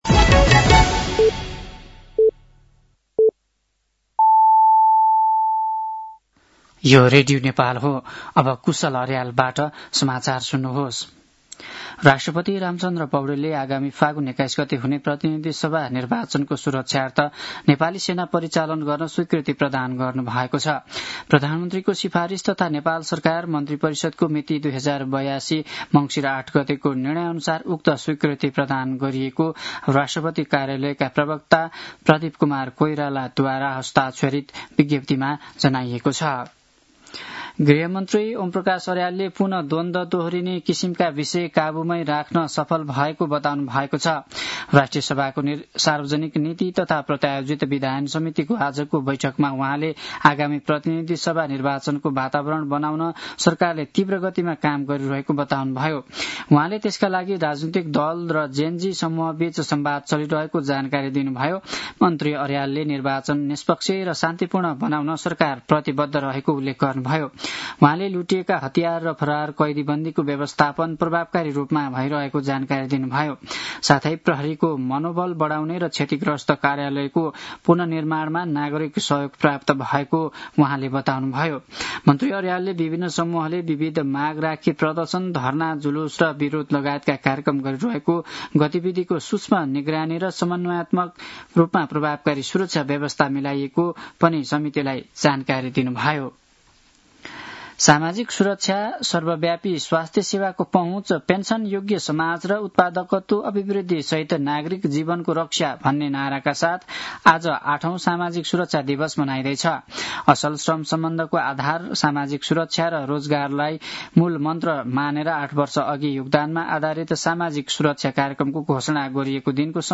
साँझ ५ बजेको नेपाली समाचार : ११ मंसिर , २०८२
5-pm-nepali-news-8-11.mp3